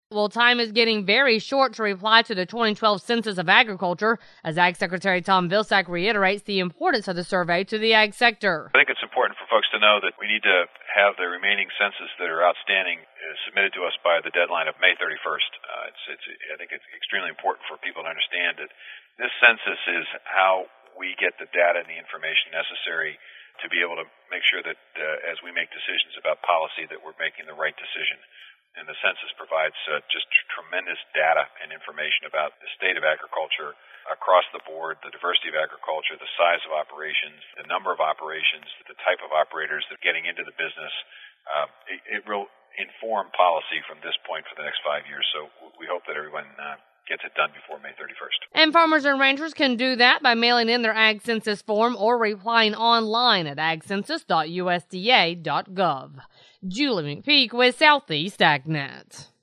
Ag Secretary Tom Vilsack reminds farmers and ranchers of the upcoming May 31, 2013 deadline to submit their information for the 2012 Census of Agriculture.